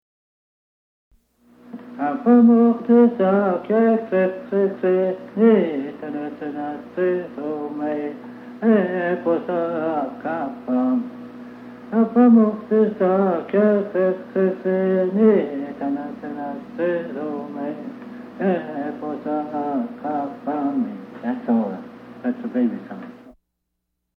Lullaby
Lullabies are known to most American Indian tribes and are still sung to small children. Typically, they have no words, and do not differ very much from other songs in their style.
Lullaby10.mp3